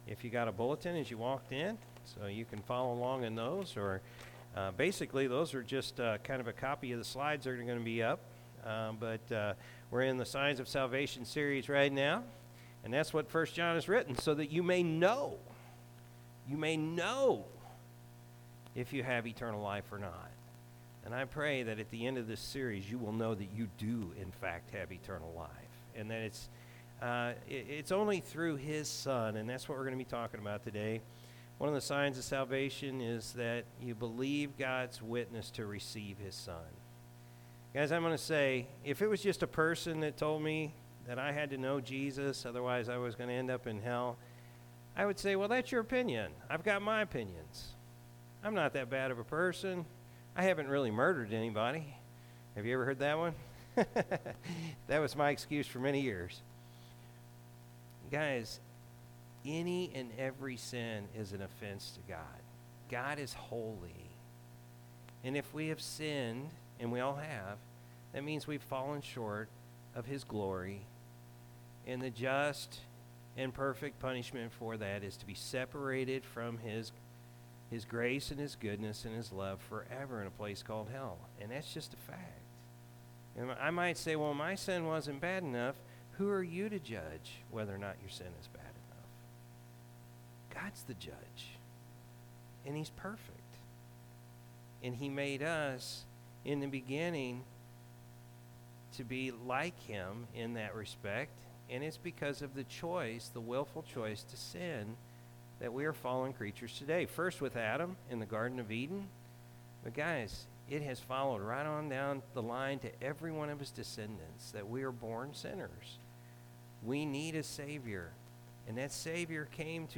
April-3-2022-Morning-Service.mp3